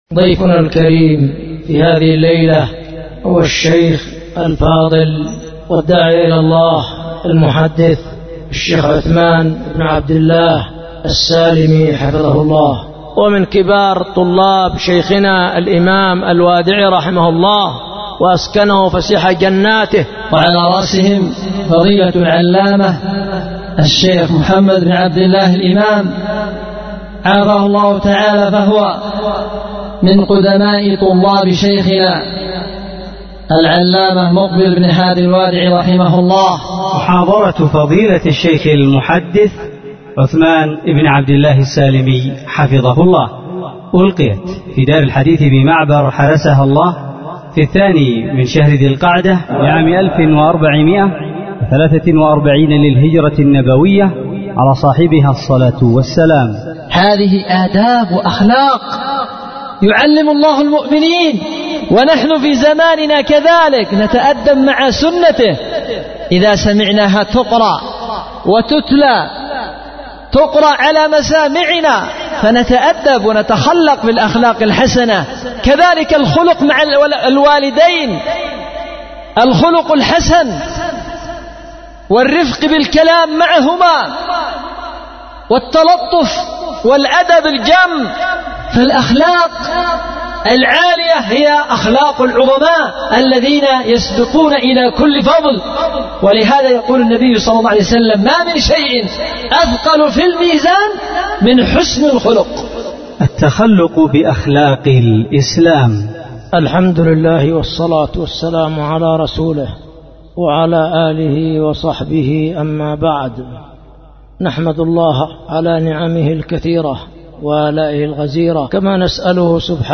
محاضرة
دار الحديث بمعبر